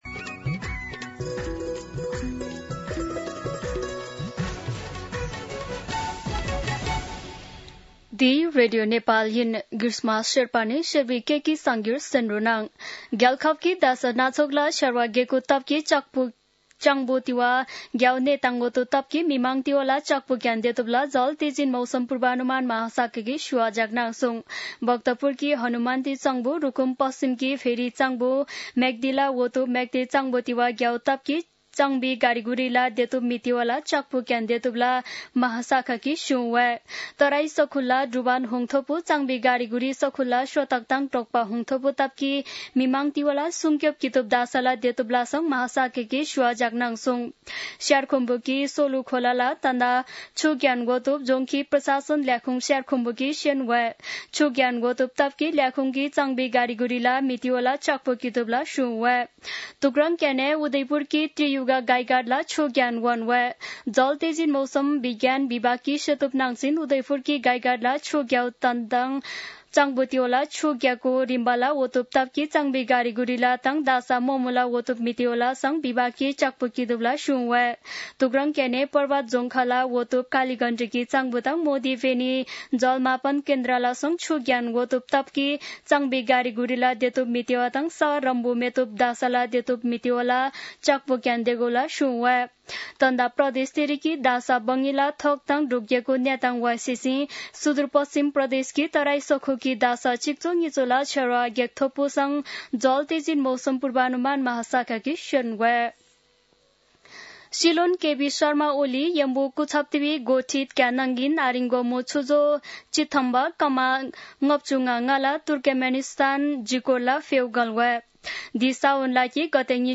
शेर्पा भाषाको समाचार : १८ साउन , २०८२
Sherpa-News-18.mp3